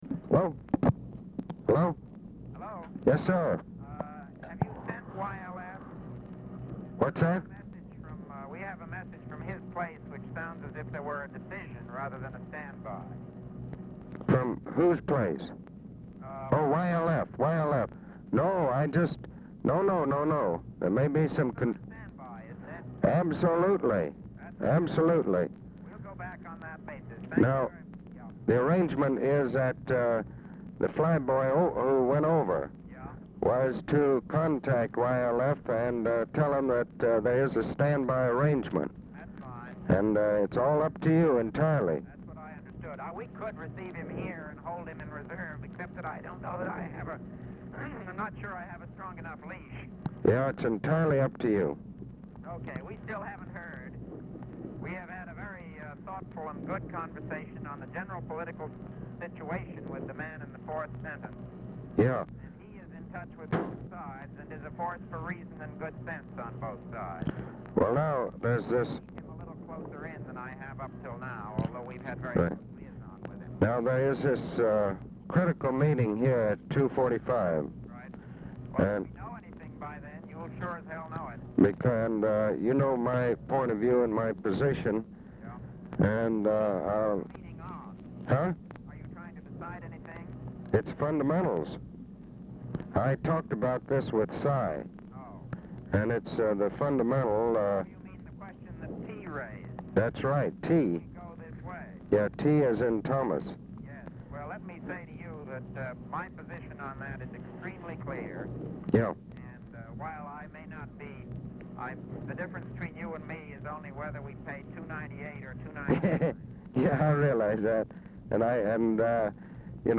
Telephone conversation # 7919, sound recording, ABE FORTAS and MCGEORGE BUNDY, 5/19/1965, 1:30PM | Discover LBJ
Format Dictation belt
Location Of Speaker 1 White House Situation Room, Washington, DC
Specific Item Type Telephone conversation Subject Defense Diplomacy Latin America